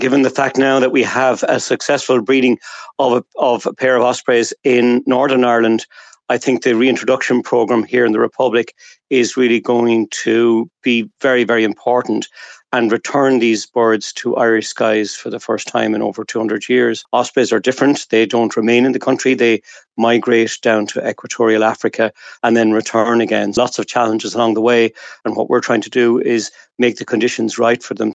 Minister of State Malcolm Noonan says the release of these chicks is a significant step towards what he hopes will be their permanent return to this country………….